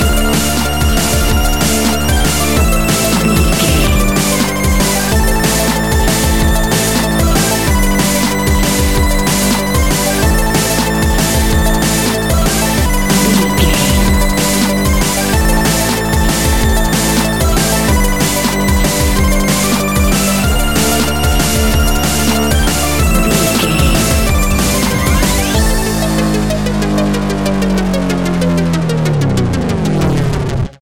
Epic / Action
Fast paced
Aeolian/Minor
intense
energetic
dark
aggressive
drum machine
electronic
sub bass
synth leads
synth bass